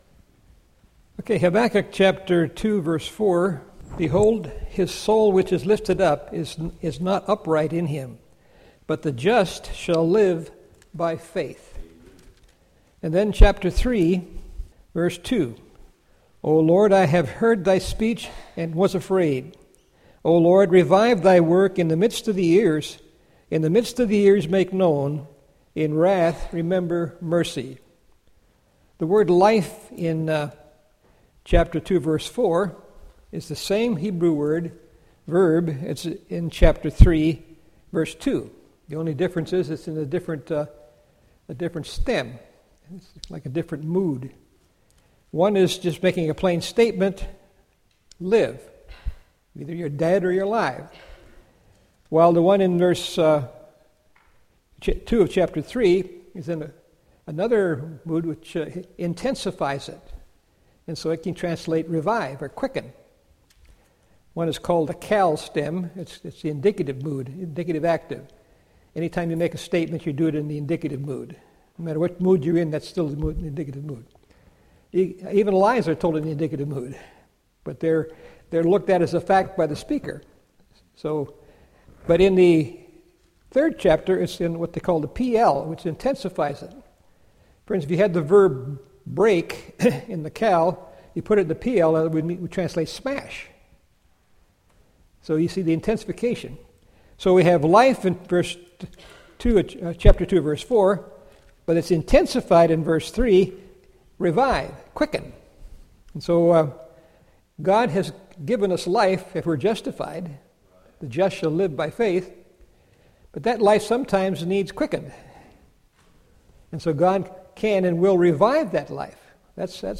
Series: 2015 July Conference Session: Morning Session